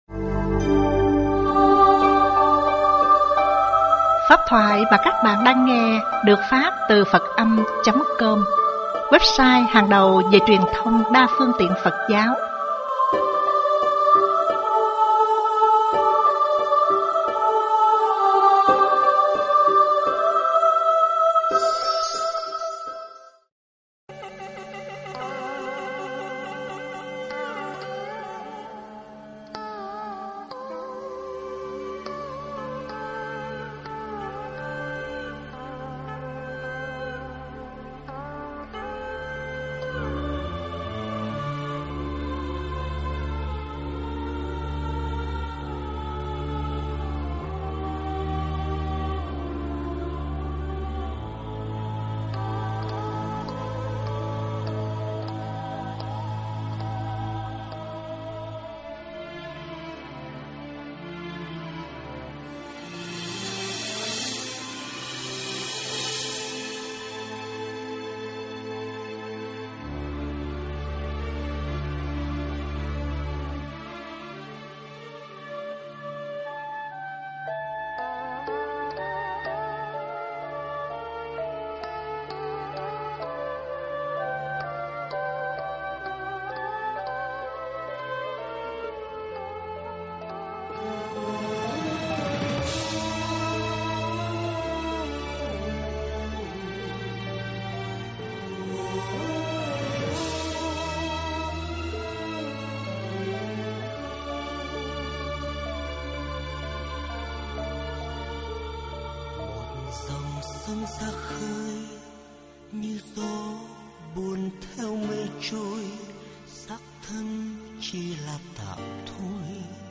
Nghe mp3 thuyết pháp Đức Phật và 5 Biểu Hiện
giảng tại tu viện Trúc Lâm